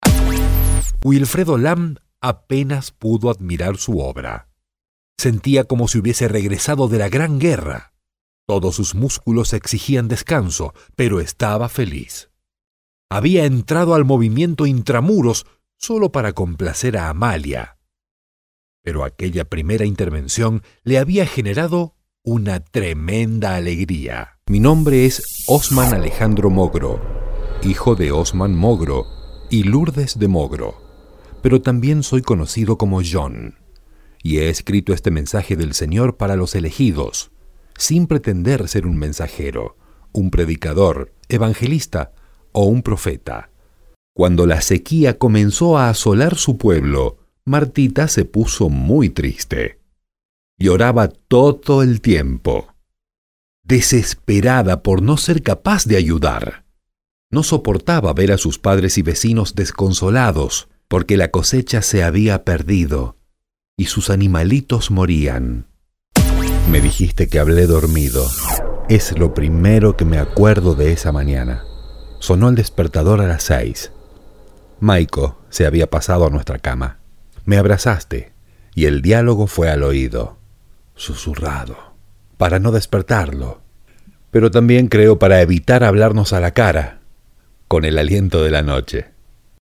Locutor argentino, español neutro,voz Senior,
Sprechprobe: Sonstiges (Muttersprache):